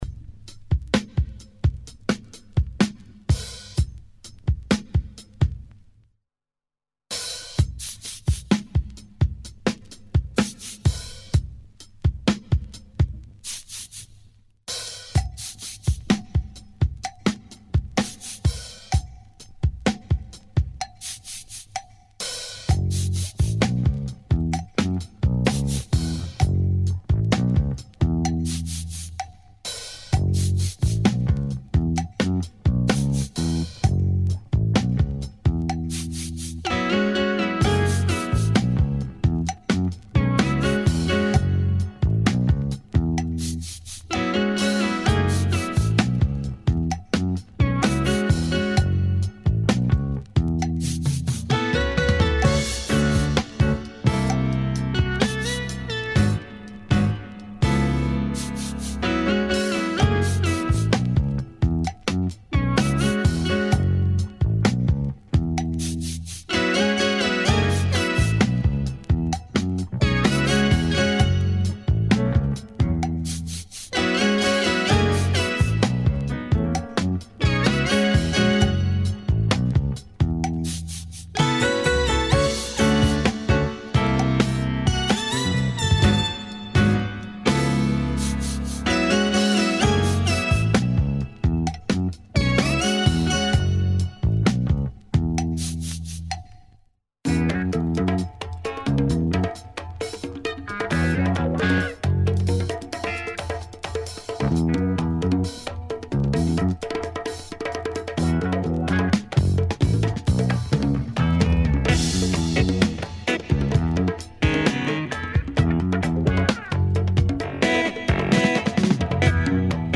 downtempo break beat track
fantastic drum break and LOOPs ! ), the funky spacy
several other groovy tracks